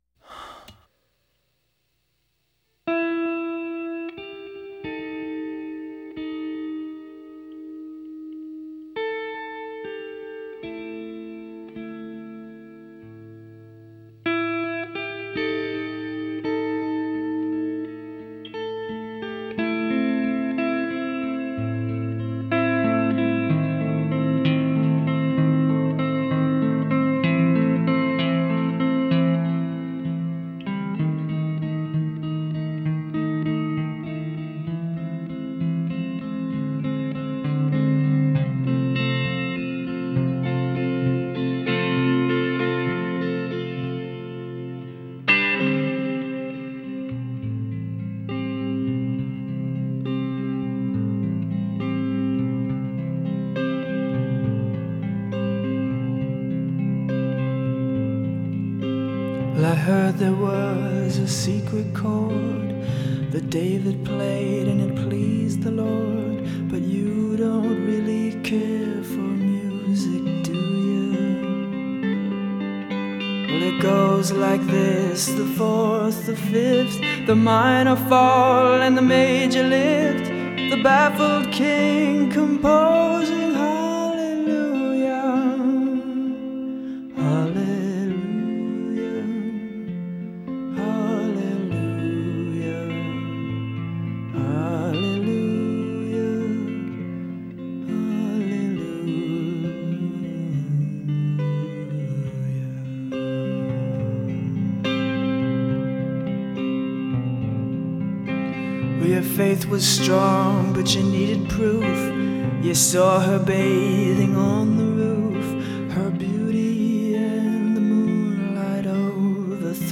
“exquisitely sung,”